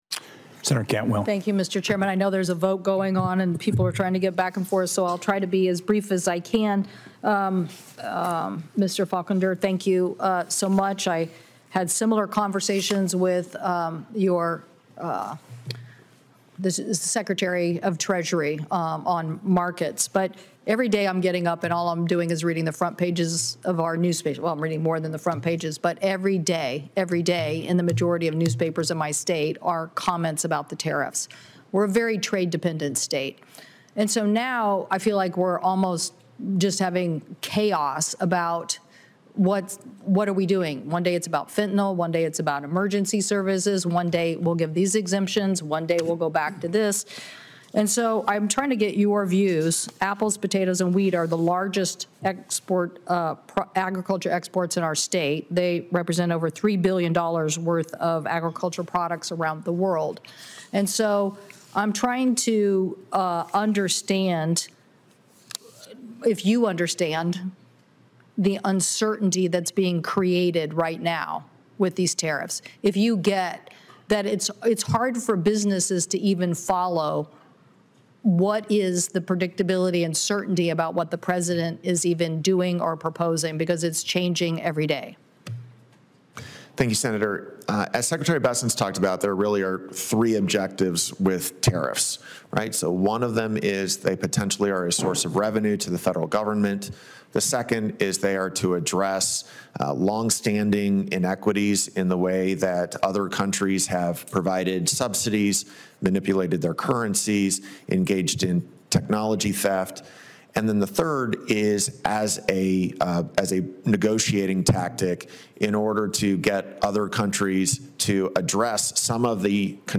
WASHINGTON, D.C. – Today, U.S. Senator Maria Cantwell (D-WA), senior member of the Senate Finance Committee and ranking member of the Senate Committee on Commerce, Science, and Transportation, pressed Michael Faulkender – President Donald Trump’s pick to serve as Deputy Treasury Secretary – on the whiplash caused by the administration’s ever-changing tariff policies.
In Senate Finance Committee, Cantwell highlights whiplash for manufacturers, growers, & consumers due to an administration that changes its tariff policies on a near-daily basis; In WA state, 2 out of every 5 jobs are tied to trade-related industries WASHINGTON, D.C.